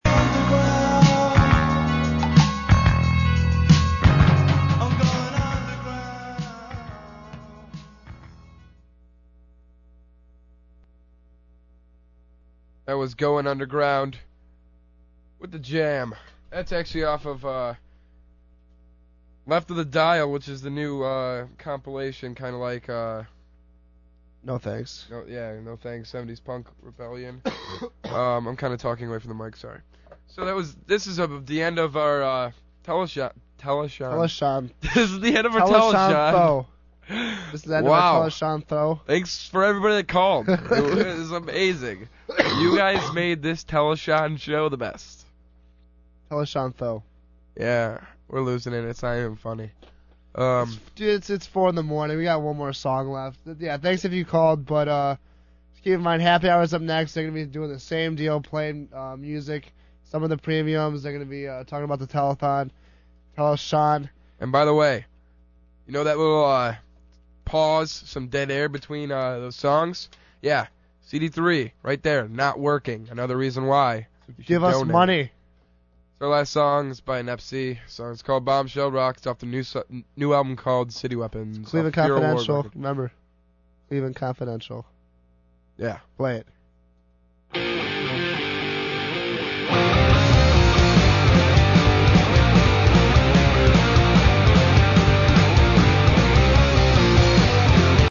• Listen to how positive and upbeat each programmer is when talking about the fundraiser.